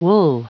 Prononciation du mot wool en anglais (fichier audio)
Prononciation du mot : wool